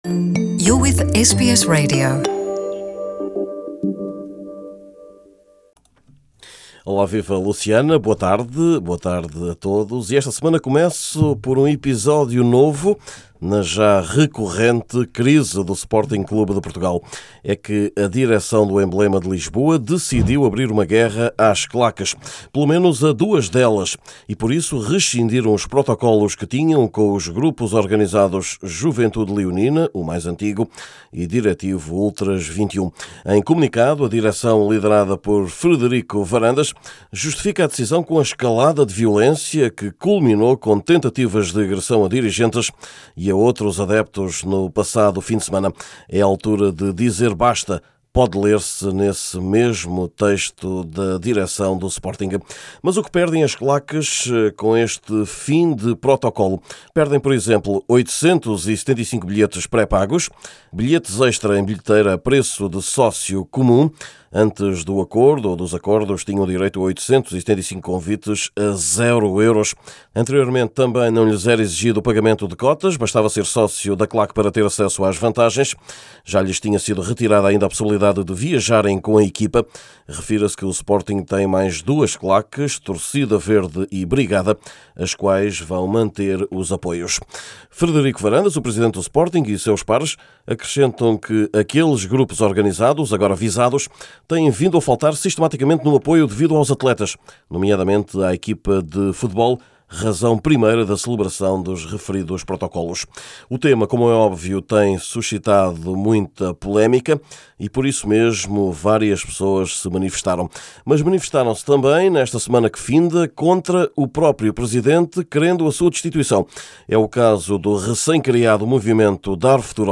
Neste boletim semanal, também espaço para a participação lusa nas provas europeias de clubes, para o (bom) momento de Jorge Jesus no Brasil ou para o descontentamento do piloto Miguel Oliveira para com a sua equipa.